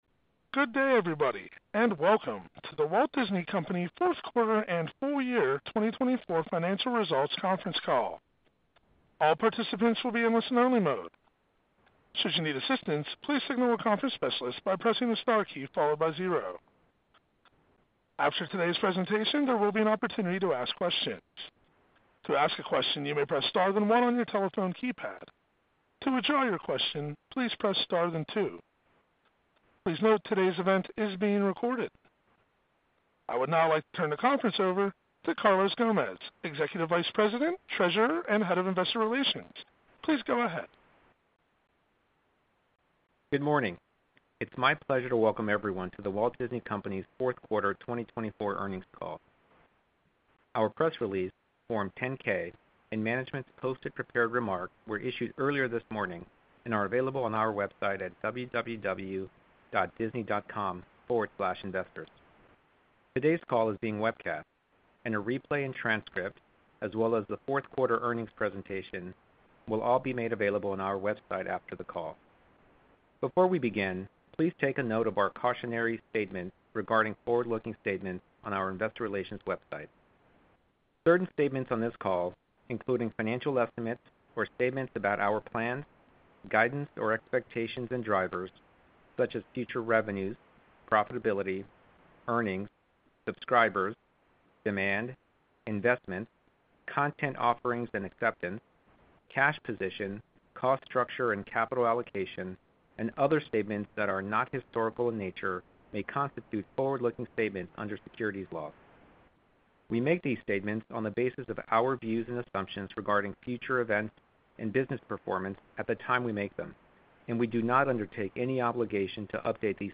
Disney’s Q4 FY24 Earnings Results Webcast